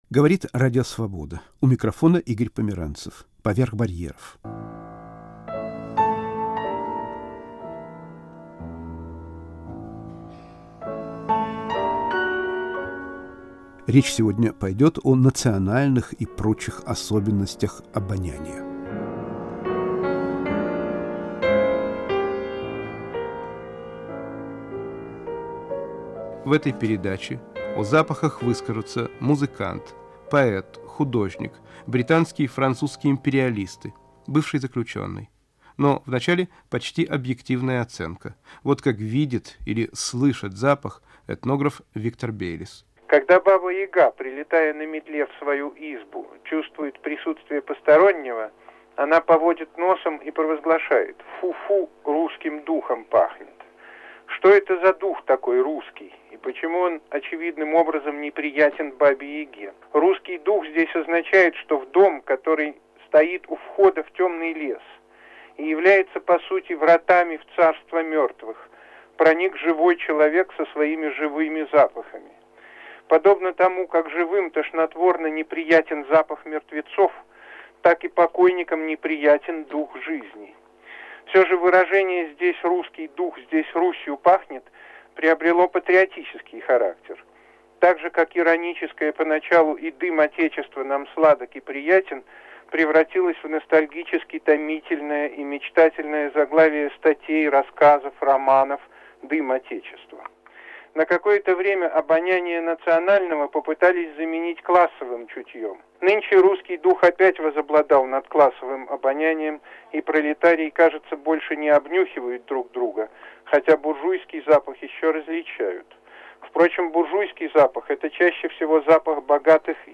Разговор о национальных и прочих особенностях запаха